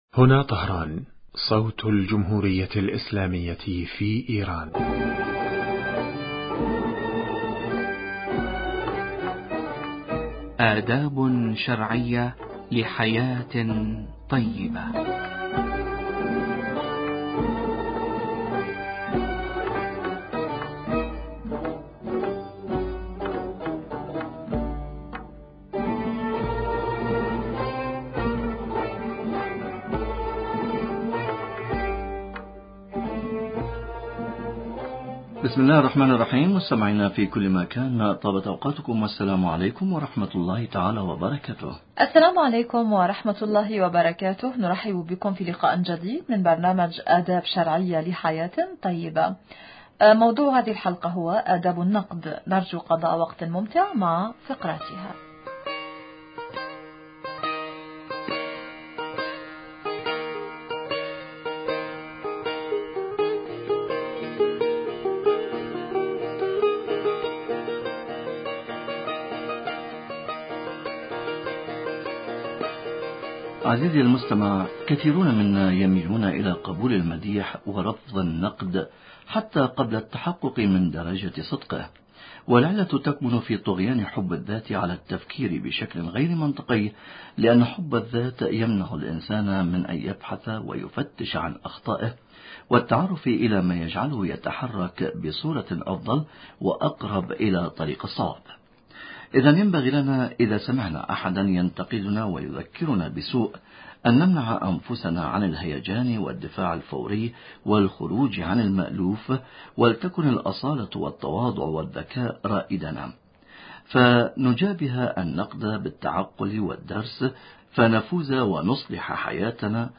ضيف البرنامج